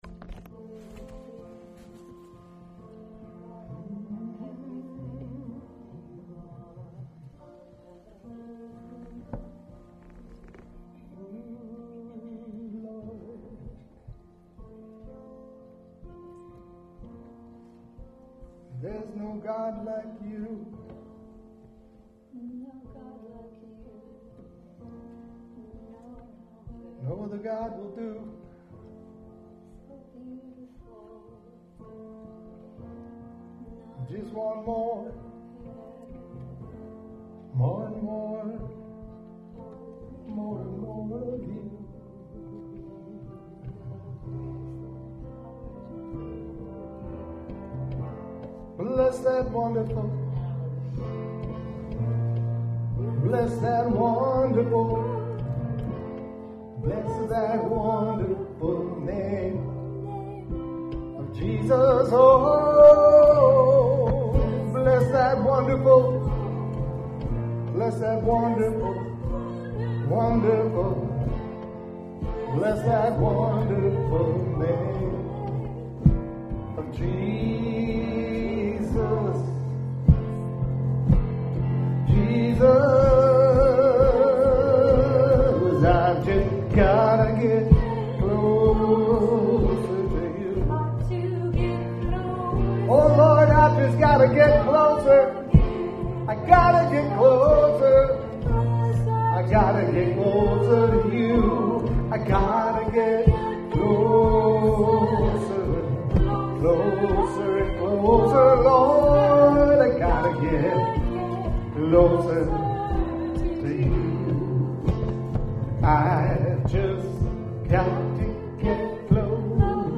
WORSHIP 0126.mp3